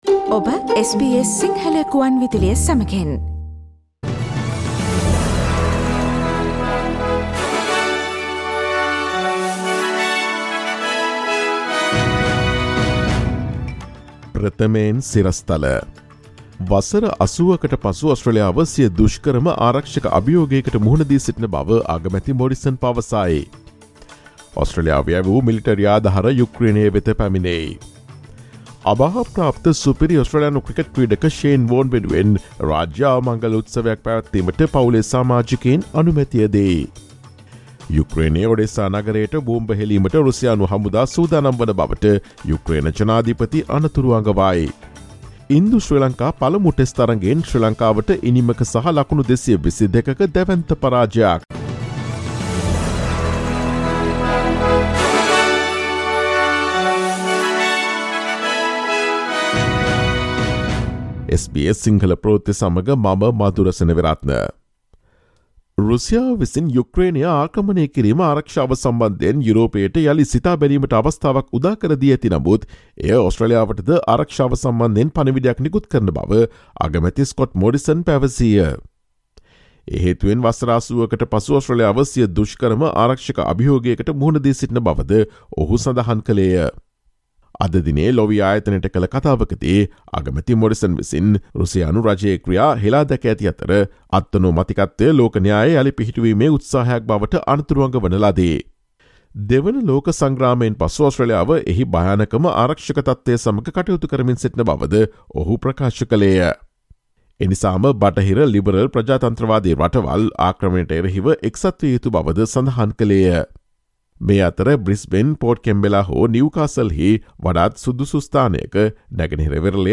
ඔස්ට්‍රේලියාවේ නවතම පුවත් මෙන්ම විදෙස් පුවත් සහ ක්‍රීඩා පුවත් රැගත් SBS සිංහල සේවයේ 2022 මාර්තු 07 වන දා සඳුදා වැඩසටහනේ ප්‍රවෘත්ති ප්‍රකාශයට සවන් දීමට ඉහත ඡායාරූපය මත ඇති speaker සලකුණ මත click කරන්න.